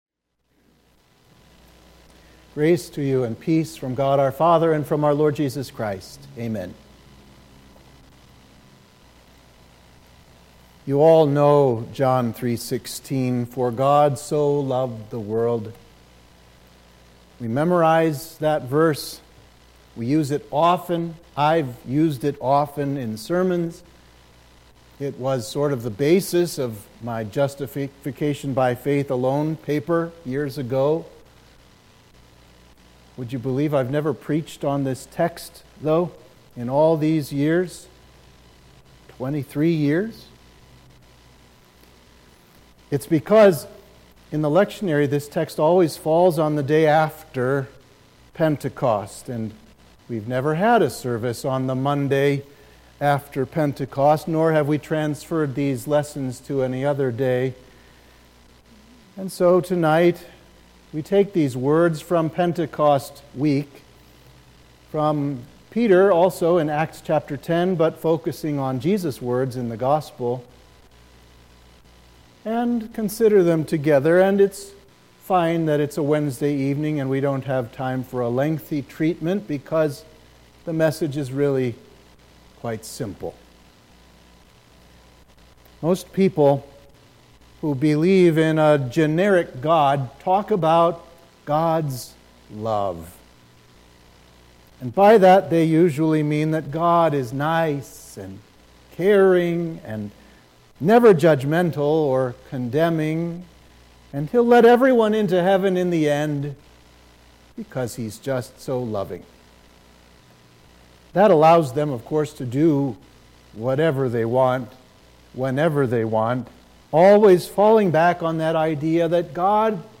Sermon for Midweek of Pentecost